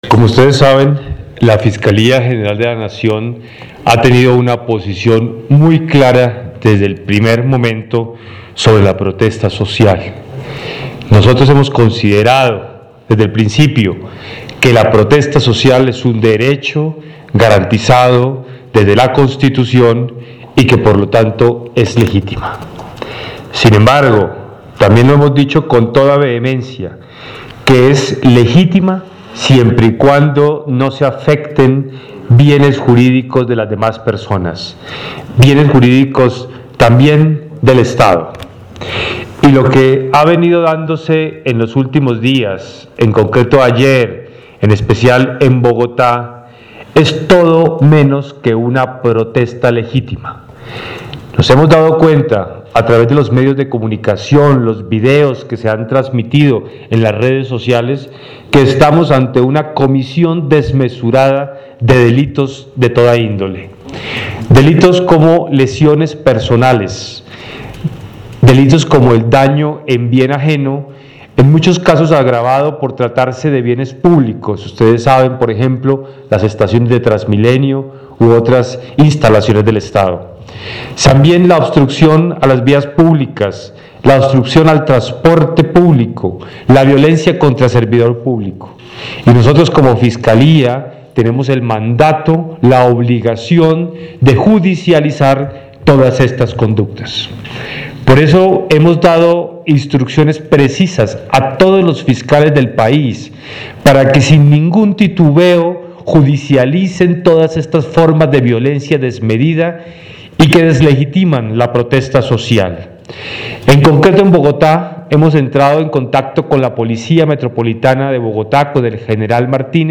Declaraciones del Vicefiscal General de la Nación, Jorge Fernando Perdomo Torres
Lugar: Nivel Central Fiscalía General de la Nación, Bogotá, D. C.